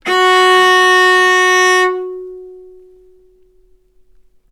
healing-soundscapes/Sound Banks/HSS_OP_Pack/Strings/cello/ord/vc-F#4-ff.AIF at f6aadab7241c7d7839cda3a5e6764c47edbe7bf2 - healing-soundscapes - Ligeti Zentrum Gitea
vc-F#4-ff.AIF